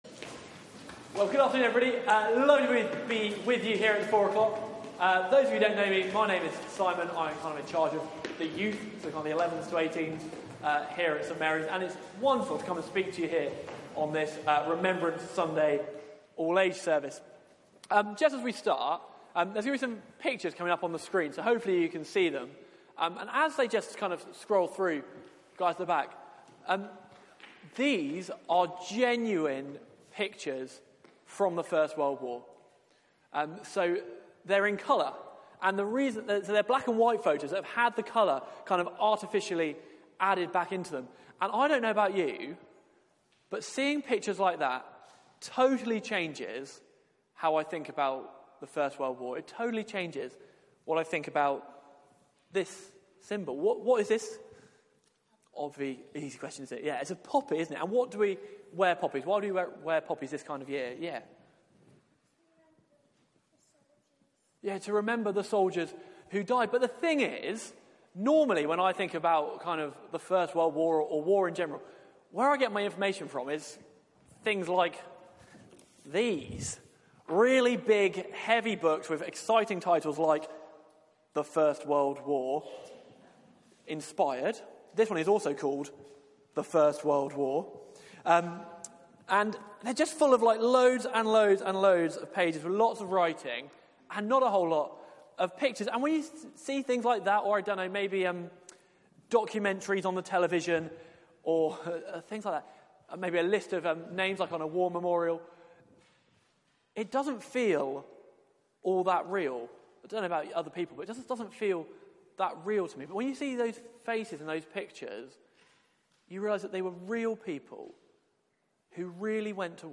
Media for 4pm Service on Sun 11th Nov 2018 16:00 Speaker
Series: Remembrance Theme: Sermon Search the media library There are recordings here going back several years.